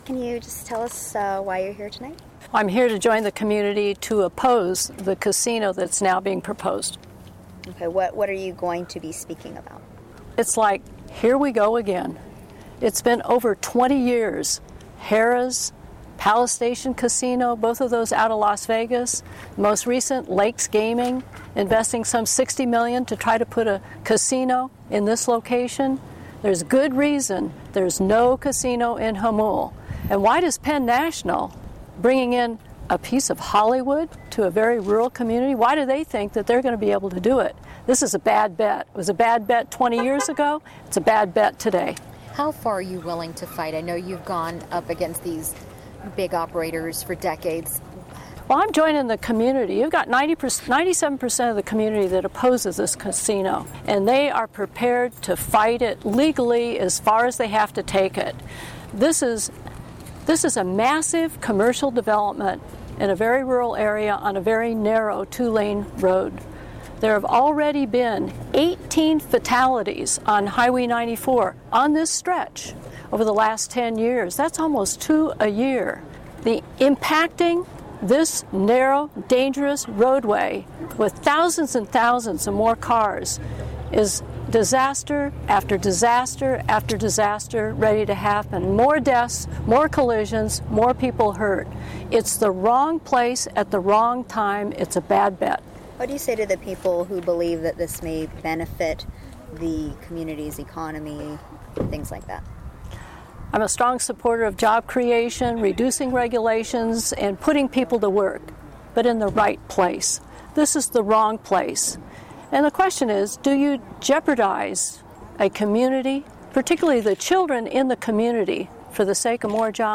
Supervisor Dianne Jacob joined Jamulians who oppose the casino, and before the formal meeting began she addressed the press.
Hear audio of Supervisor Jacob.